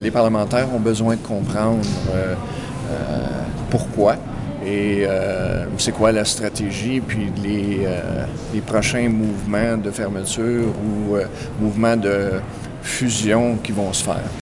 Le député libéral, Guy Ouellette, explique l’objectif de la démarche.